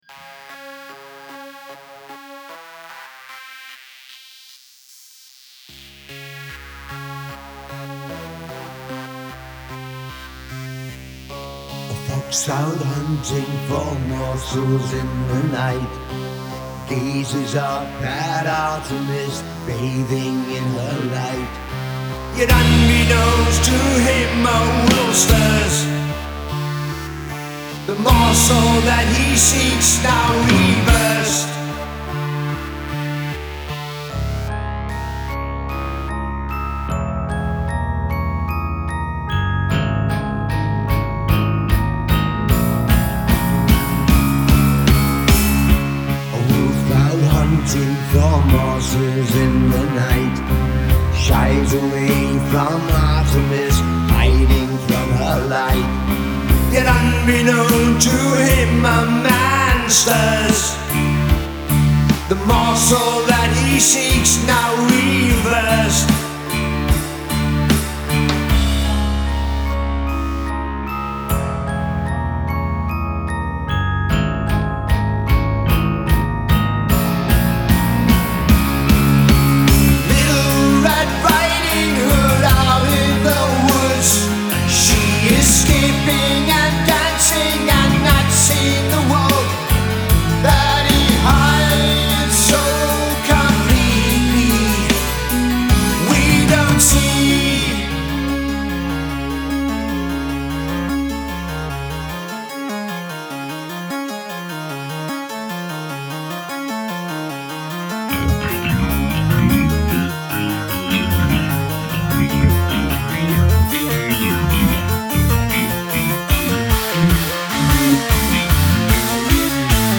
keyboards/drums
bass/lead vocals
guitars/backing vocals
It's a dark album that draws you in, and holds you there…